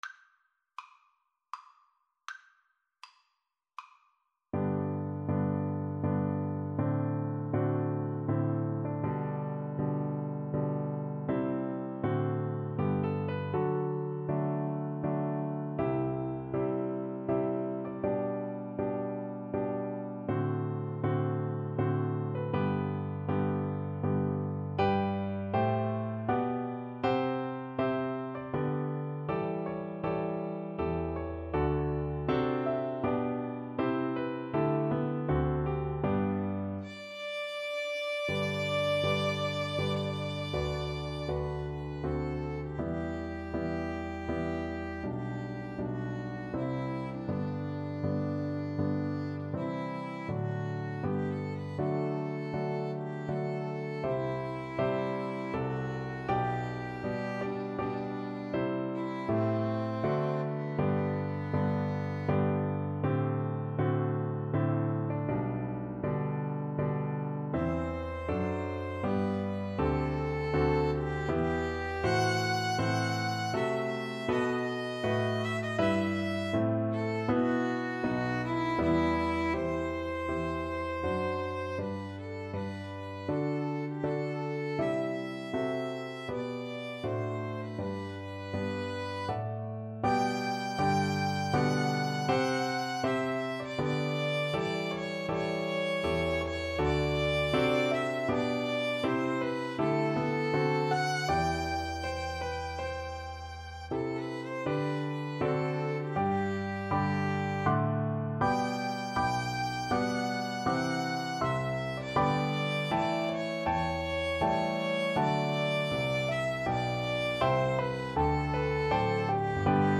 Free Sheet music for String trio
G major (Sounding Pitch) (View more G major Music for String trio )
Andante grandioso